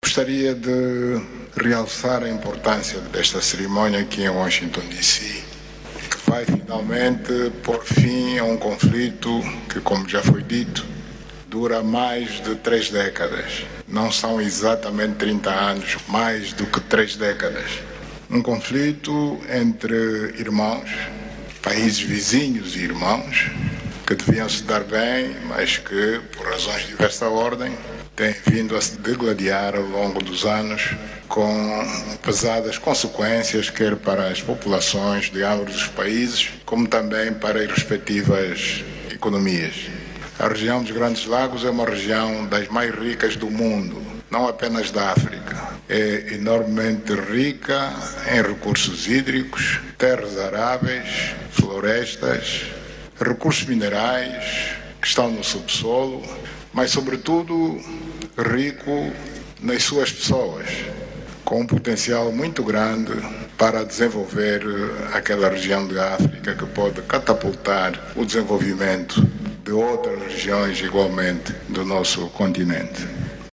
O líder da União Africana participou ontem, em Washington DC, na cerimónia de assinatura do referido Acordo e declarou que a guerra na RDC é completamente inaceitável.